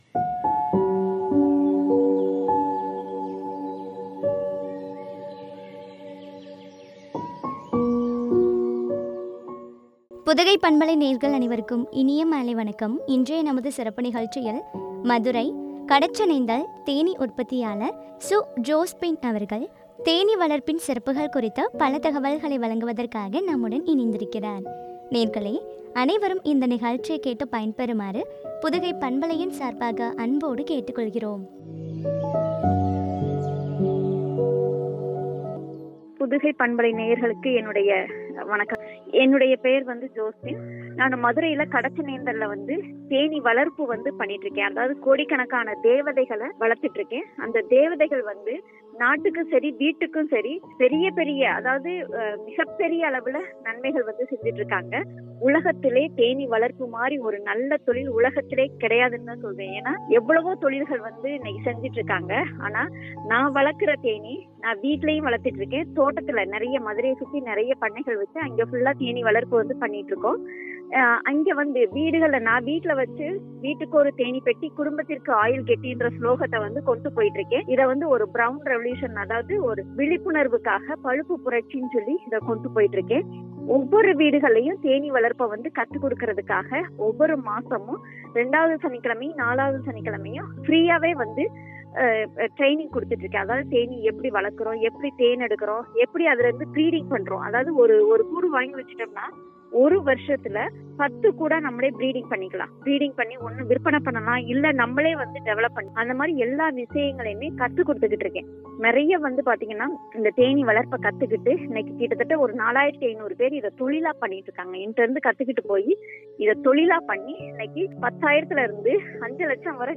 தேனீ வளர்ப்பின் சிறப்புகள் பற்றிய உரையாடல்.